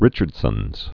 (rĭchərd-sənz)